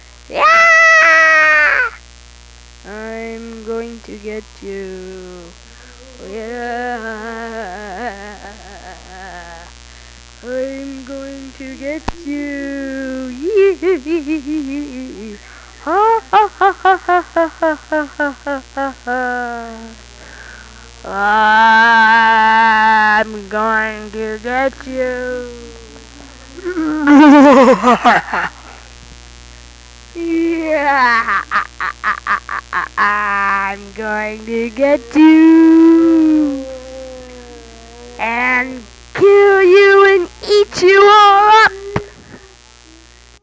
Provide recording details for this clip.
How to remove this high pitch noise? That’s award-winning US power hum and buzz. Somebody has a microphone with a broken wire in the cable, a dead shield, or a bad connection.